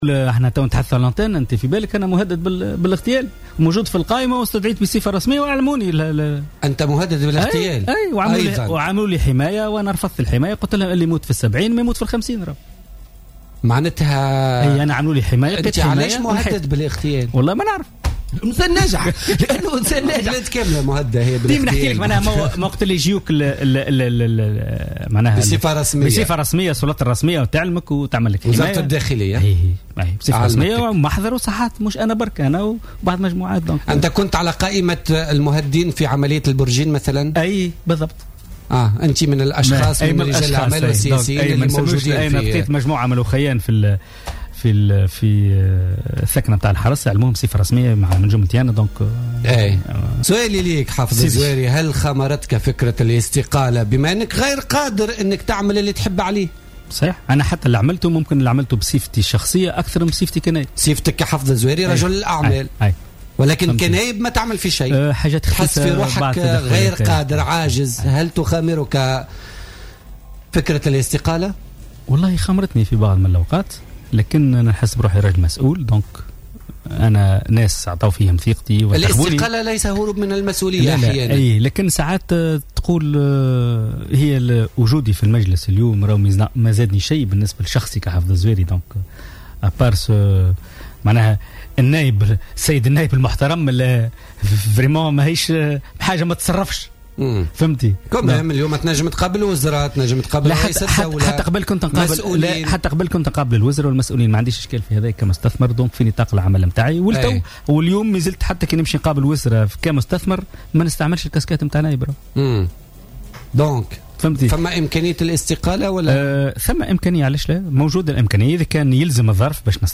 وأضاف الزواري في مداخلة له اليوم الثلاثاء في برنامج "بوليتيكا" أن وزارة الداخلية أعلمته بمخطط لاستهدافه وقامت بتوفير الحماية له لكنه رفضها، وفق تعبيره.